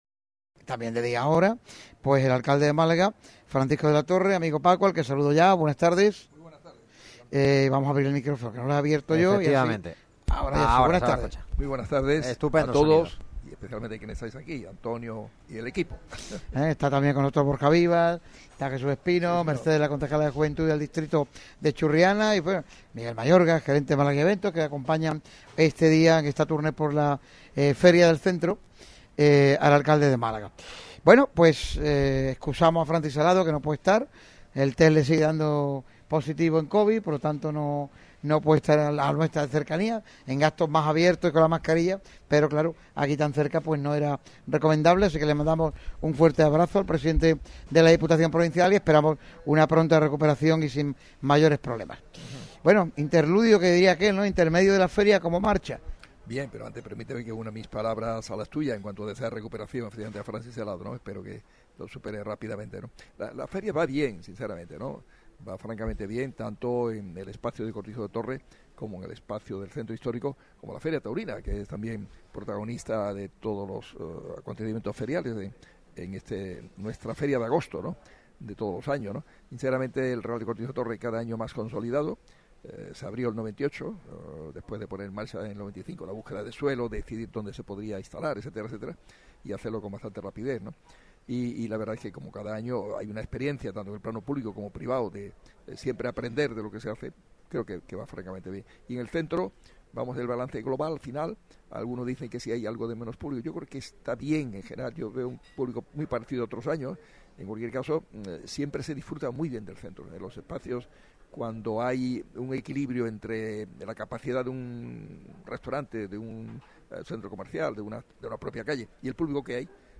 El alcalde de Málaga, Francisco De La Torre, ha concedido una entrevista en exclusiva a Radio MARCA Málaga en plena Feria de la capital desde restaurante Araboka en Calle Pedro de Toledo Nº4.